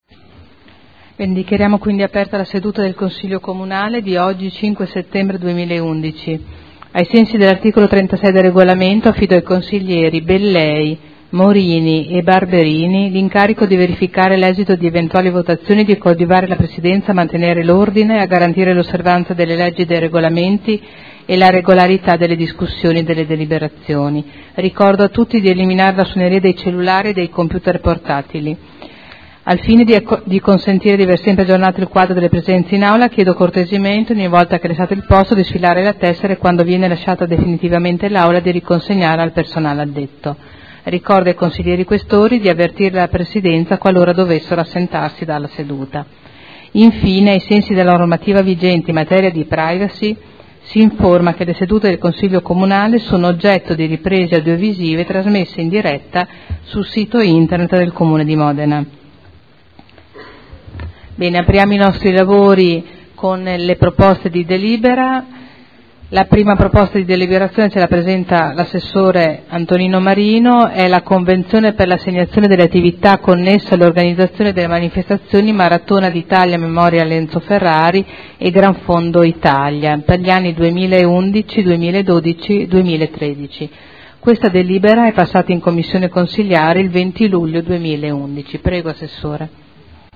Seduta del 05/09/2011. Il Presidente Caterina Liotti apre il lavori del Consiglio Comunale